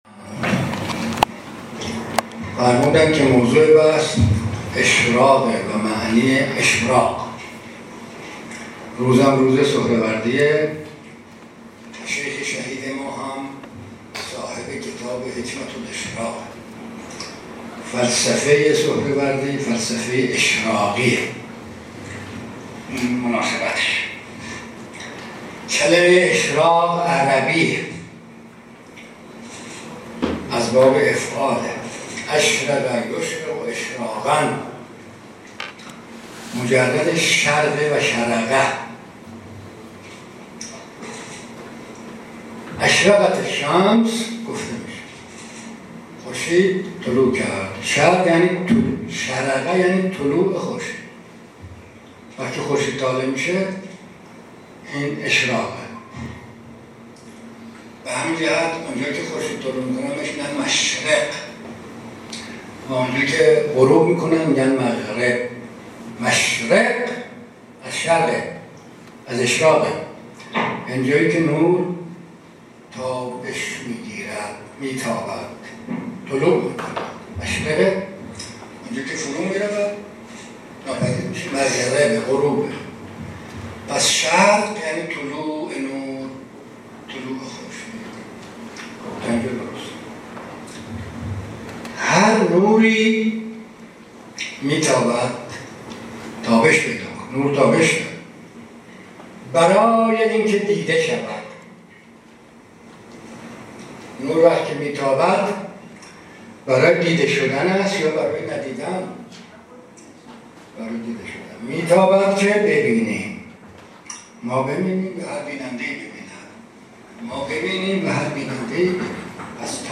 فایل صوتی این سخنرانی را در بالا می توانید بشنوید.